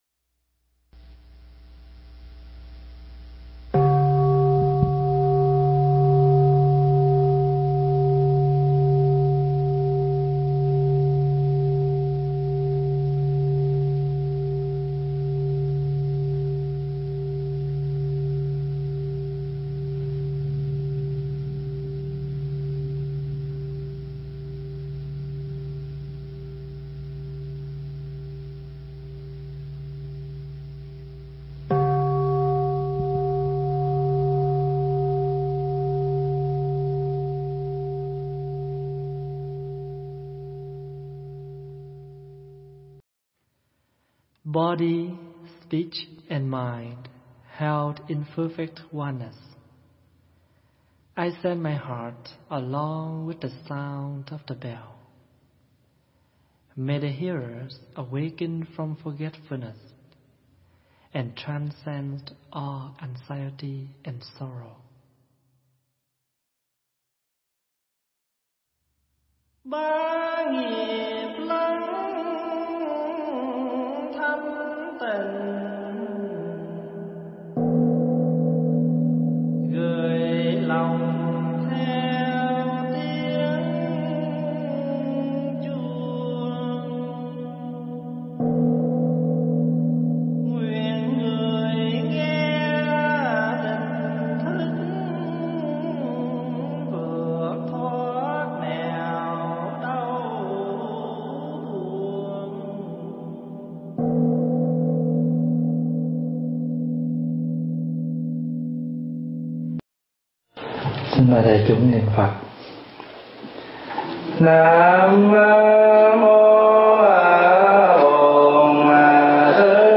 Tải mp3 Pháp Thoại Người Cư Sĩ Viên Mãn 4 – Đại Đức Thích Pháp Hòa thuyết giảng tại tu viên tây thiên, Canada, ngày 8 tháng 8 năm 2011, trong ngày song bát